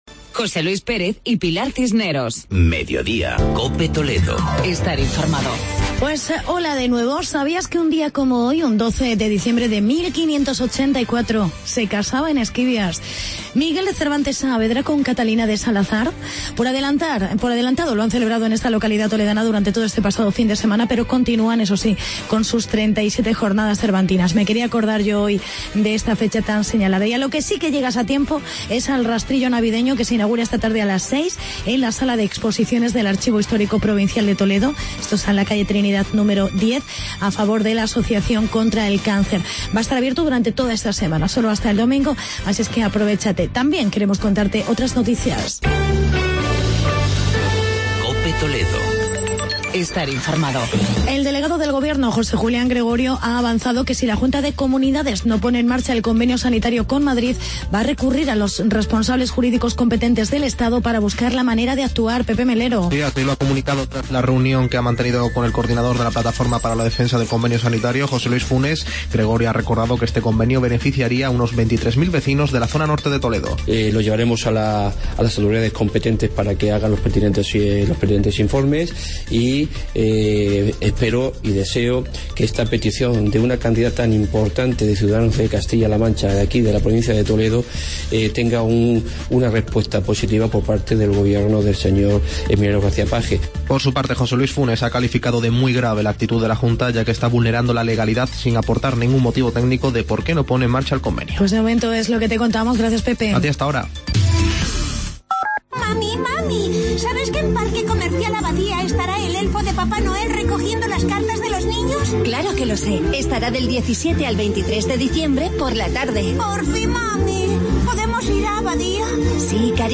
Actualidad y entrevista con Nacho Hernando, portavoz del Gobierno de CLM.